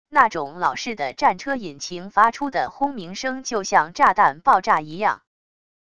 那种老式的战车引擎发出的轰鸣声就像炸弹爆炸一样wav音频